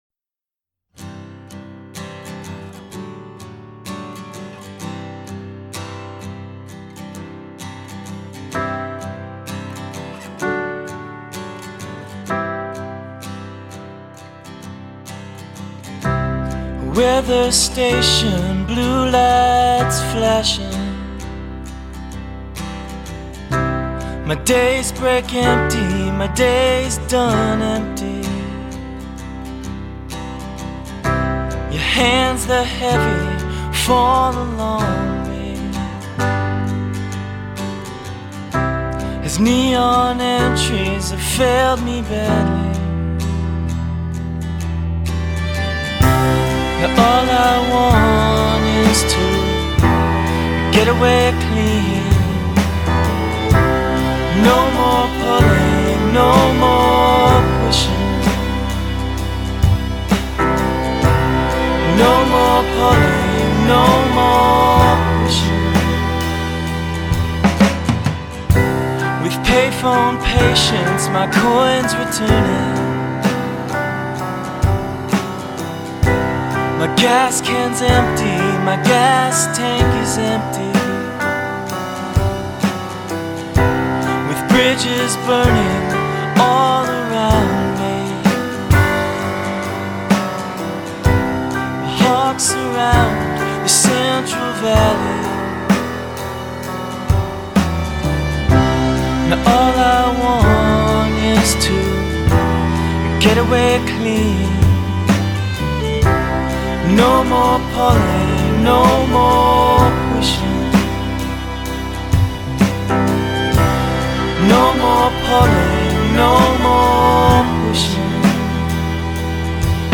It’s a beautiful folk album of strings, soul, and harmony.